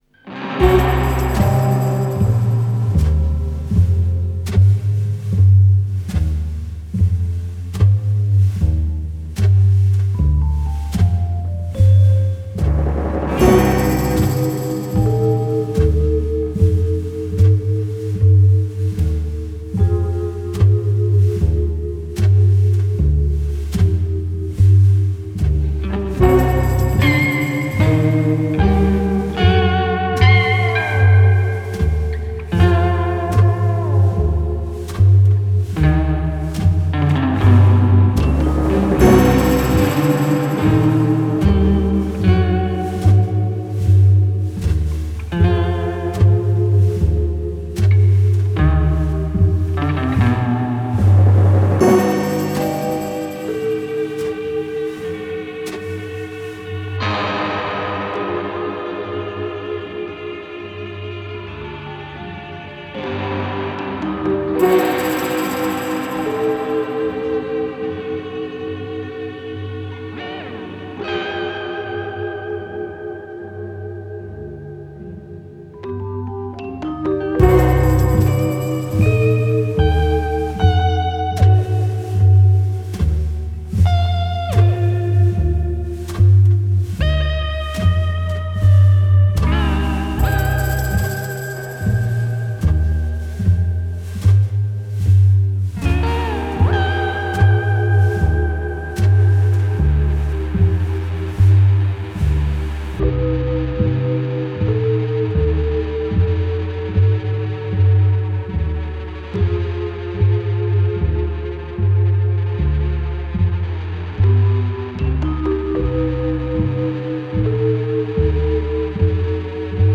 Dark scores move with menace.